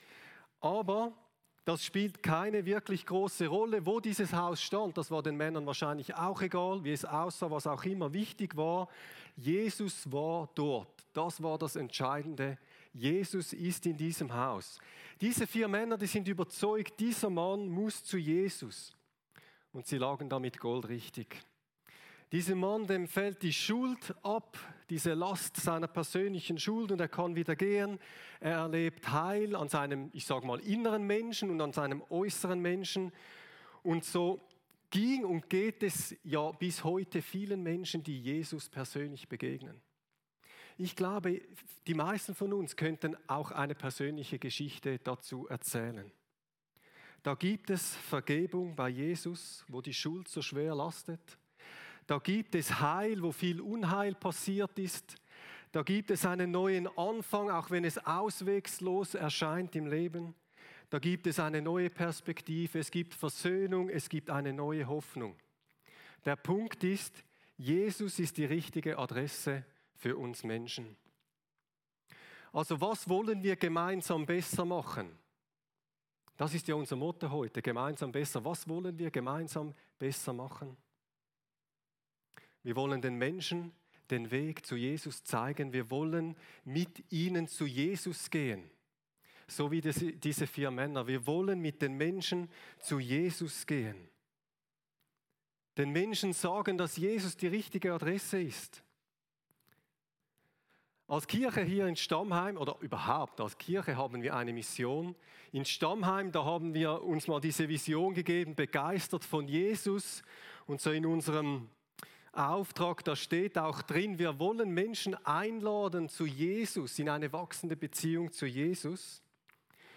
Gemeinsamer Gottesdienst der Chrischona Stammheim mit der Chrischona Stein am Rhein.